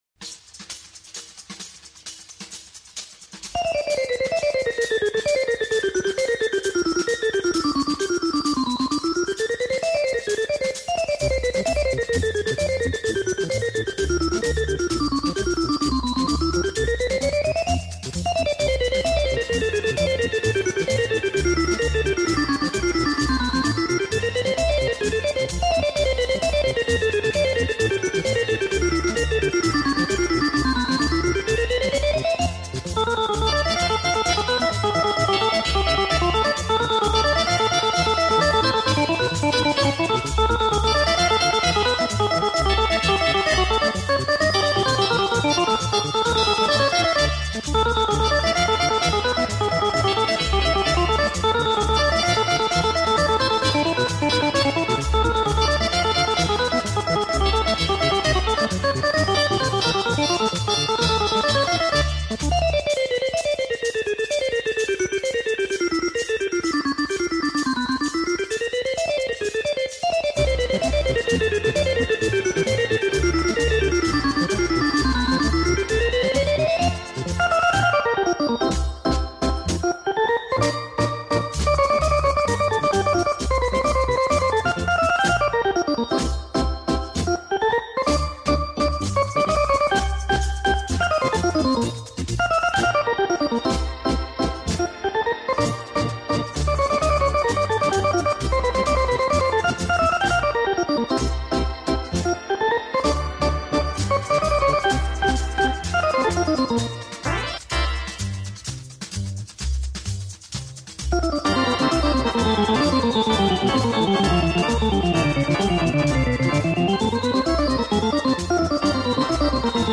Format:Vinyl, 7", EP, 45 RPM
Genre:Jazz, Latin
Style:Afro-Cuban Jazz, Cha-Cha, Easy Listening